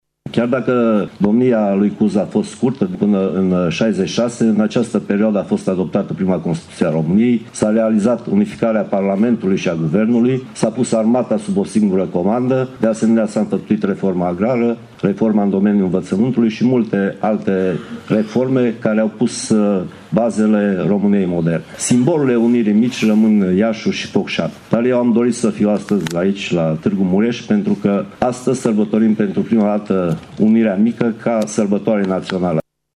Împlinirea a 156 de ani de la Unirea Principatelor Române a fost sărbătorită astăzi la Tîrgu-Mureş în curtea interioară a Inspectoratului Judeţean de Poliţie, unde au loc în fiecare an festivităţi de acest fel.
La manifestări a fost prezent şi ministrul Apărării, Mircea Duşa care a preferat să sărbătorească „Mica Unire” la Tîrgu-Mureş şi nu la Iaşi sau la Focşani: